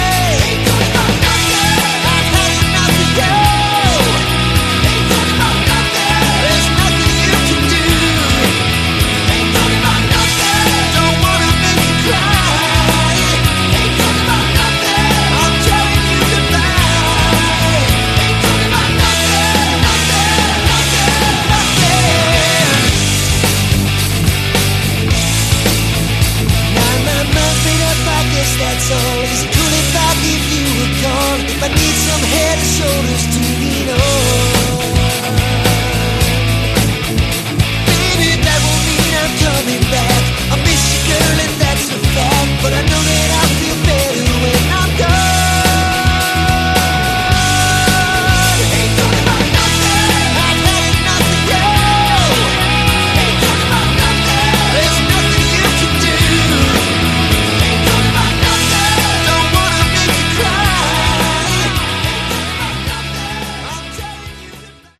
Category: Melodic Rock/Aor
lead vocals
guitars, bass, vocals
drums, keys, vocals